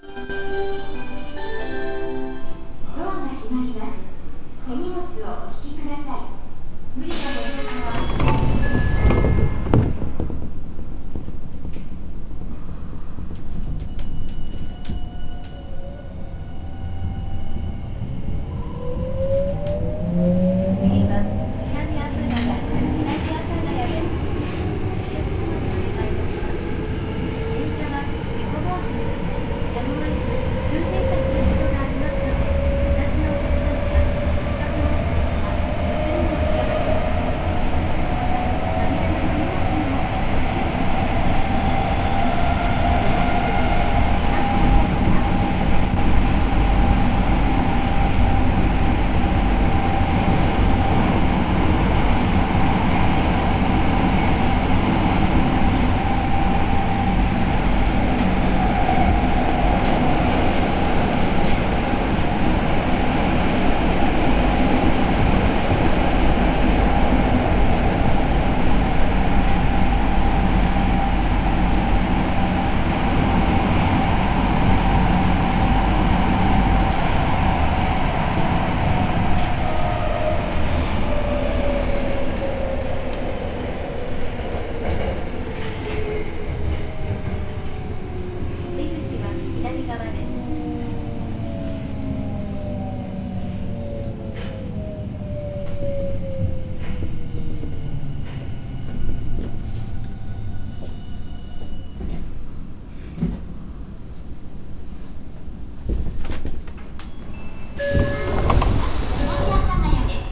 ・02系東芝IGBT車走行音
【丸ノ内線】新高円寺〜南阿佐ヶ谷（1分50秒：600KB）
05系６・７次車と同じモーターを採用していますが、第３軌条の上に線路の幅も違うので、若干モーター音が異なる気が…。